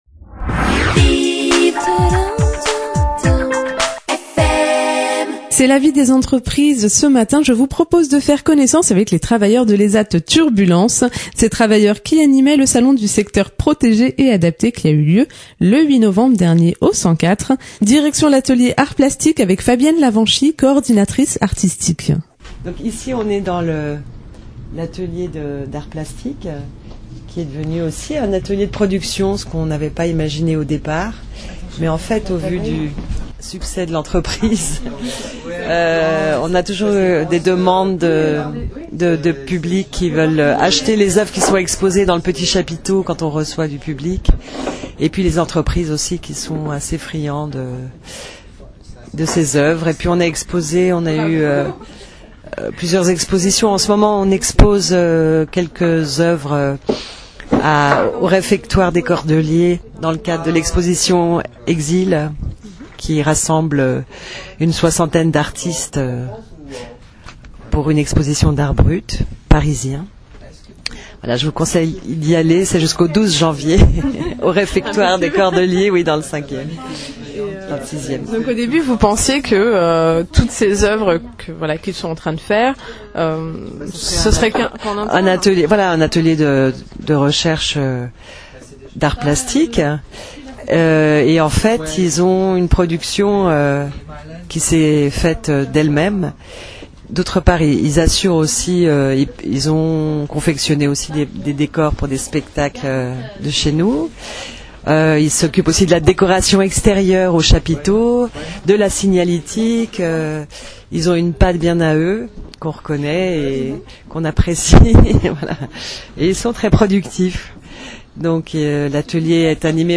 Reportage mercredi 21 décembre à 9h07 sur VIVRE fm (93.9)